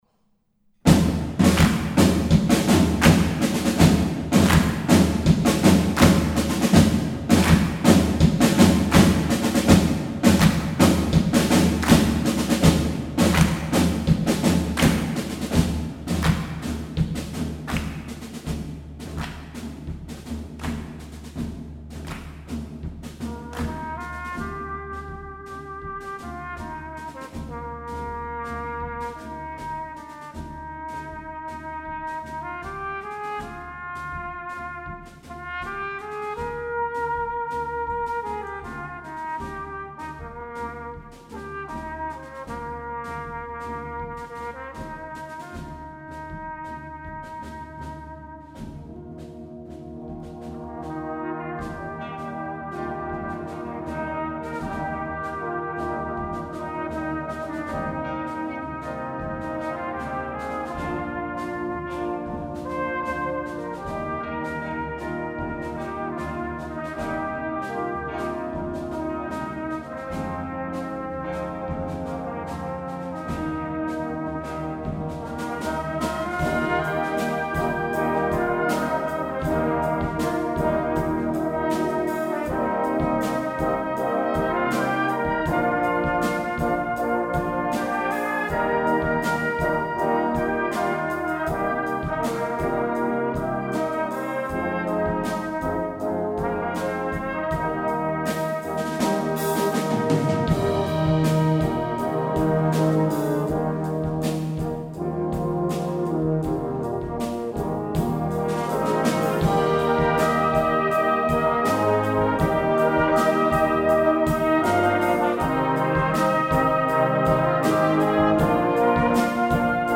Gattung: für Blasorchester
Besetzung: Blasorchester